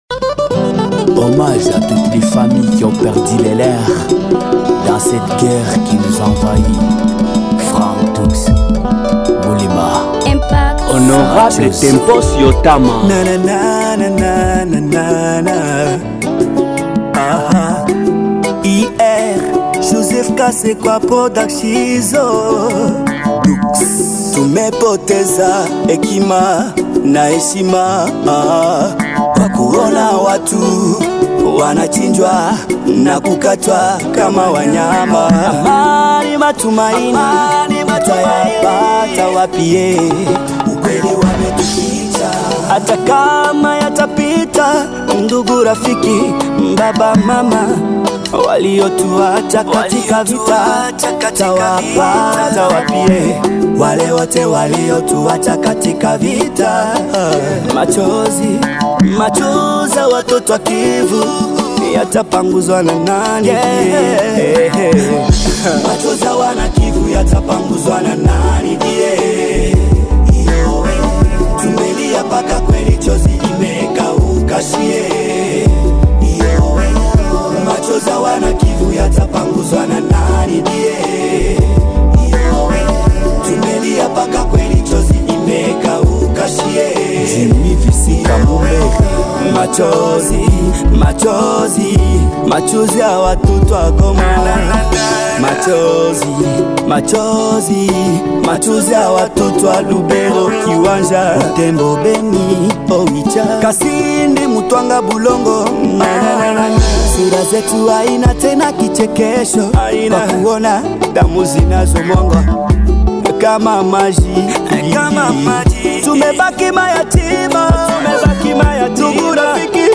en Rap And Blues